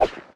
hero_roll.ogg